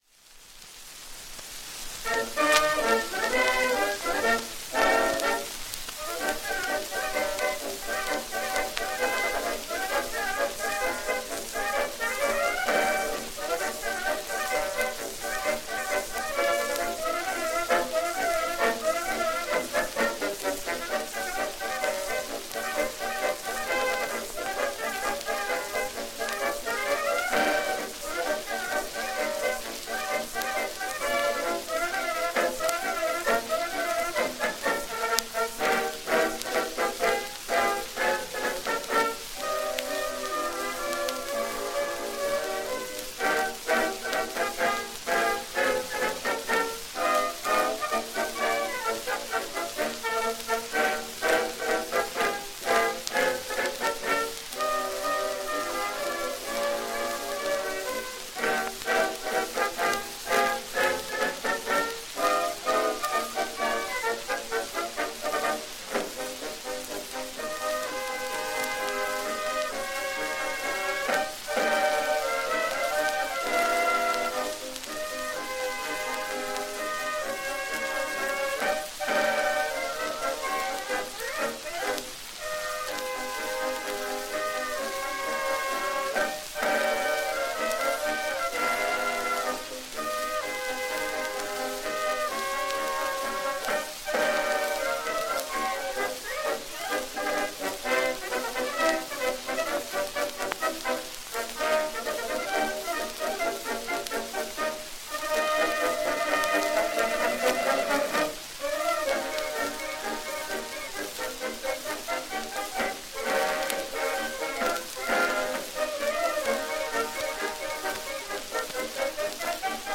Franz Von Blon composer
[80 rpm recording found here.]